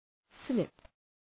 Προφορά
{slıp}